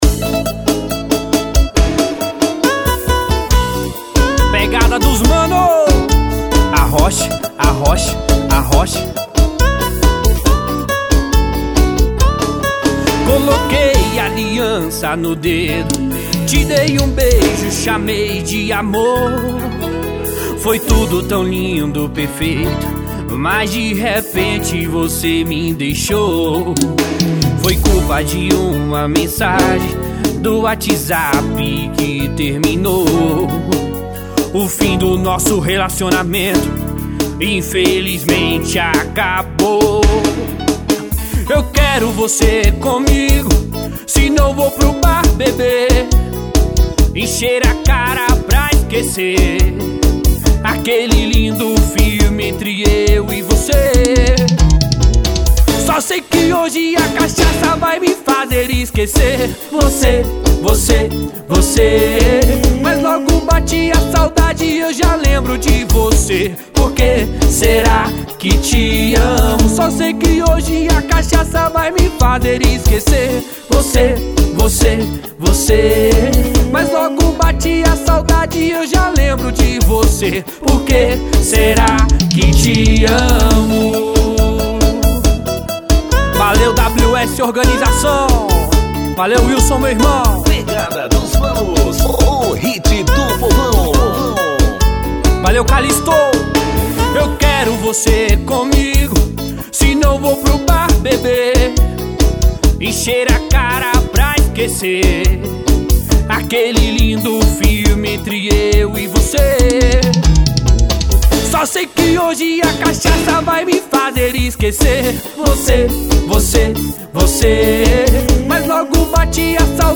EstiloArrocha